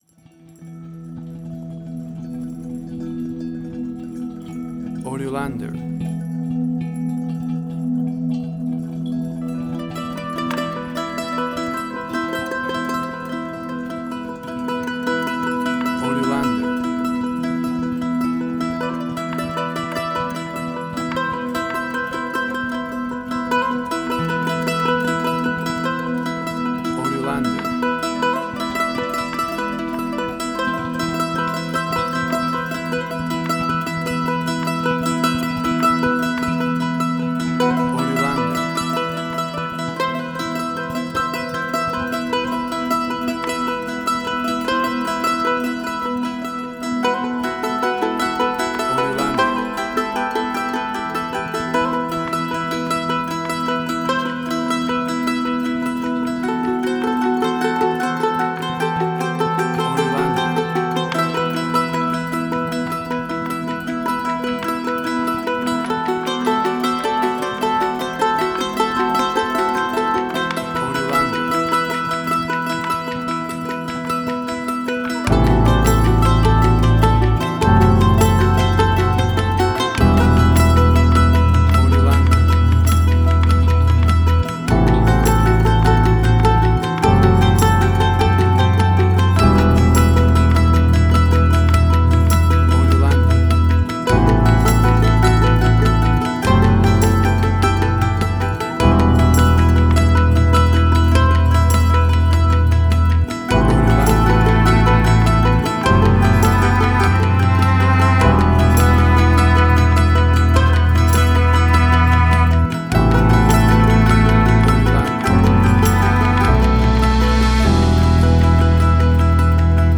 Post-Folk_Similar Beasts of the Southern Wild_Ozark.
Tempo (BPM): 102